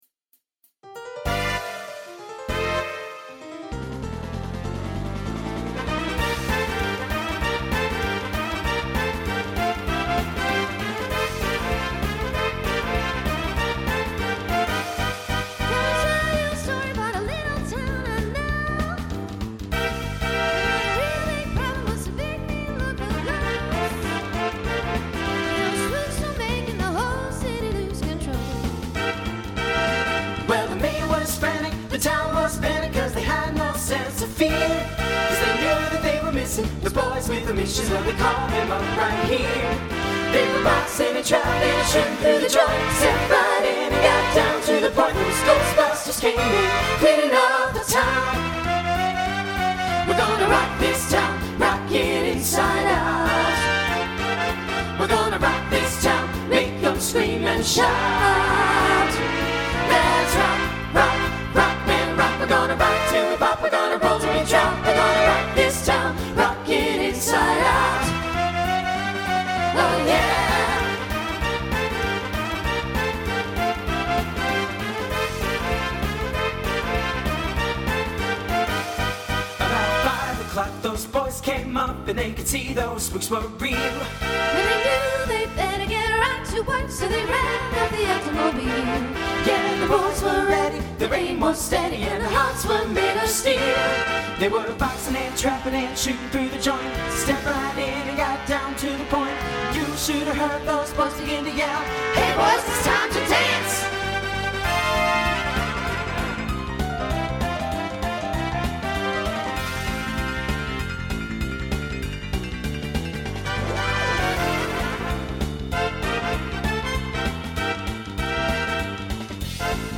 Voicing SATB Instrumental combo Genre Swing/Jazz